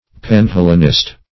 Panhellenist \Pan*hel"len*ist\, n. An advocate of Panhellenism.